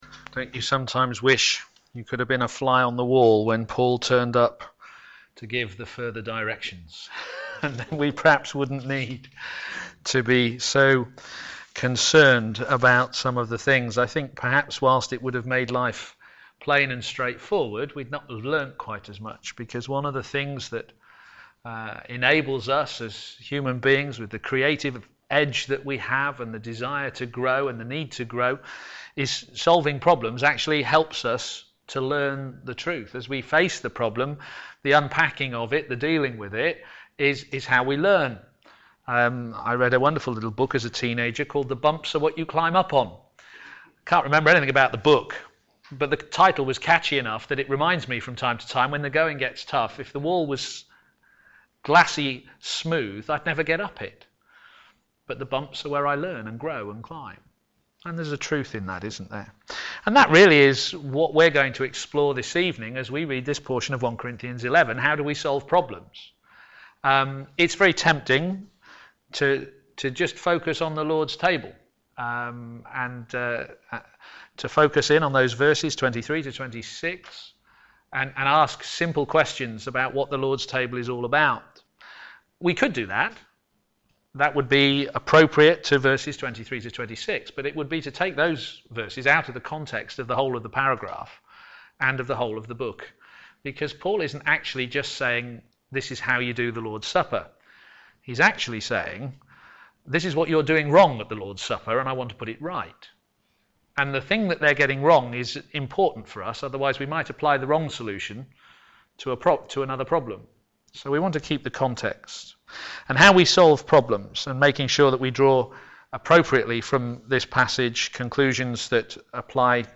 Working Together to Advance the Gospel Theme: The Lord's Supper Sermon